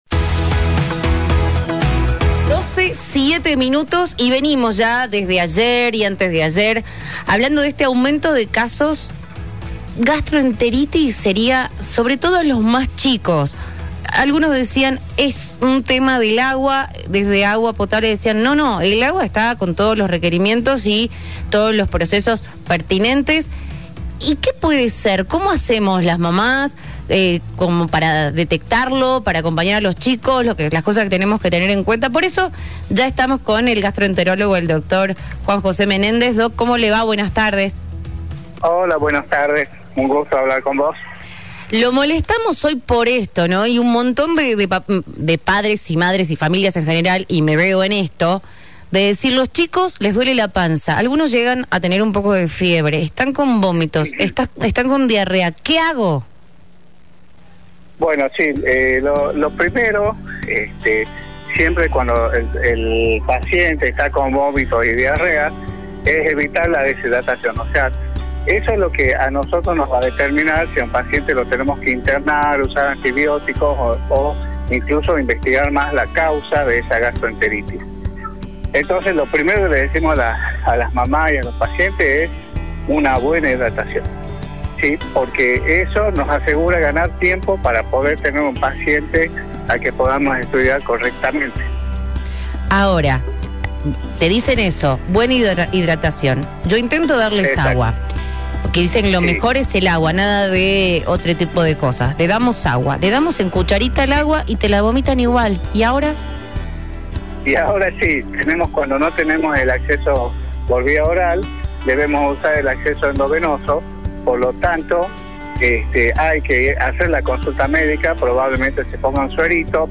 Salud mental.